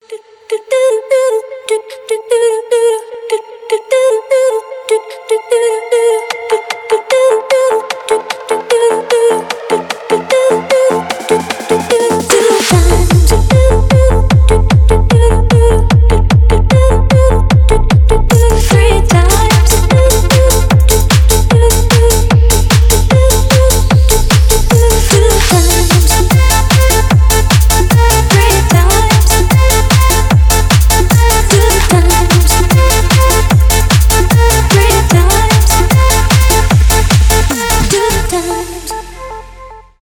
евродэнс , клубные
edm
танцевальные